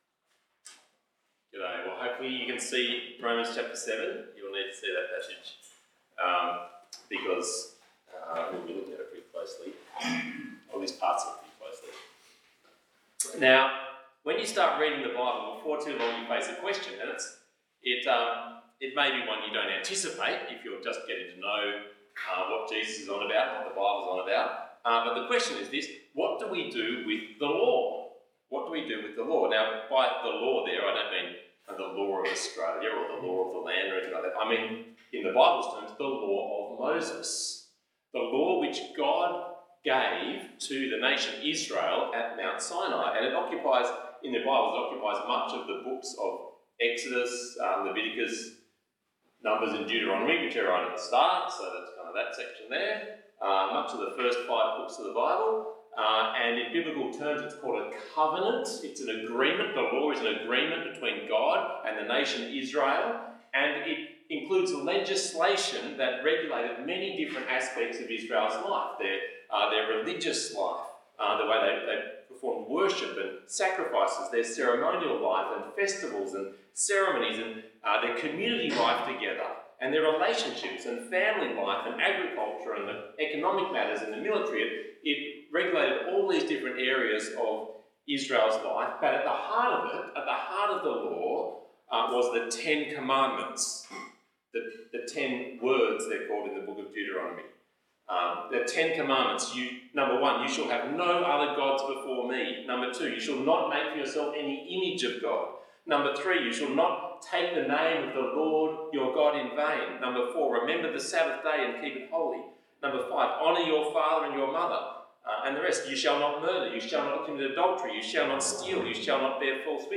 Passage: Romans 7:1-25 Talk Type: Bible Talk